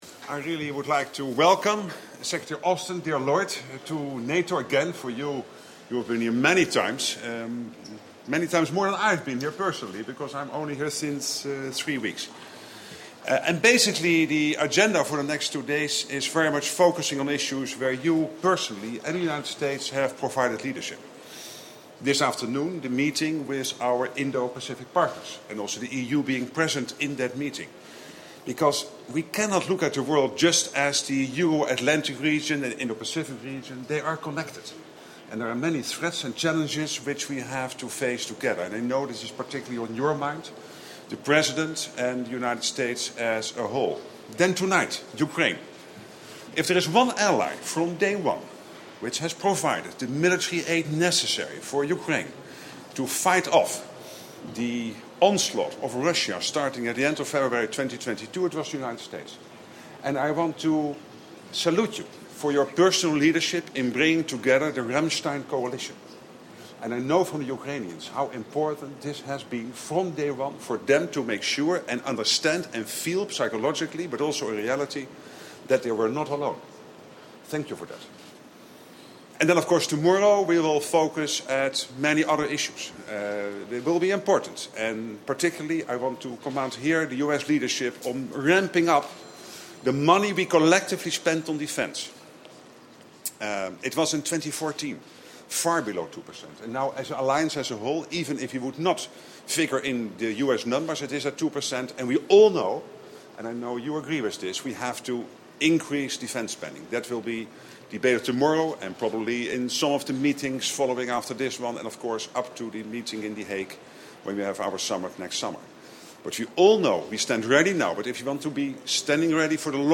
Remarks by the NATO Secretary General and the US Secretary of Defense